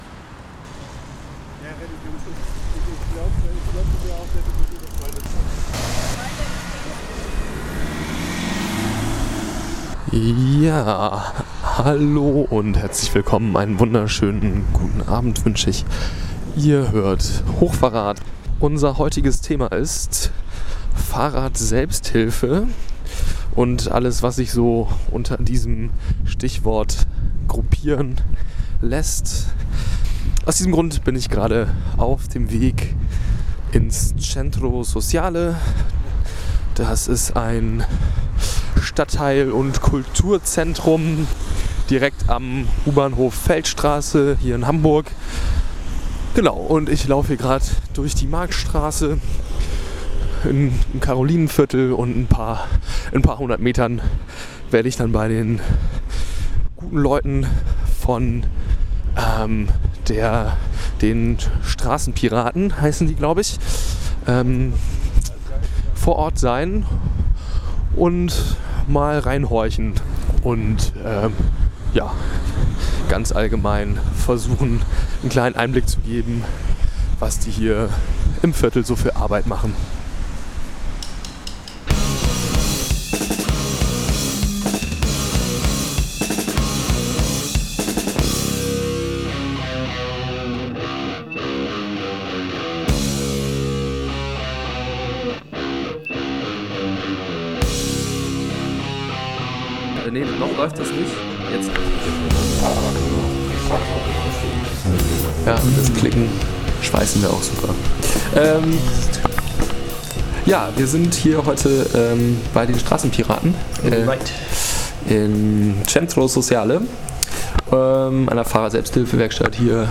Radiointerview-Hochfahrrad-strassenpiraten.mp3